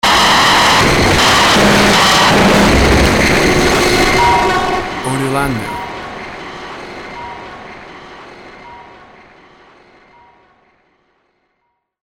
WAV Sample Rate 16-Bit Stereo, 44.1 kHz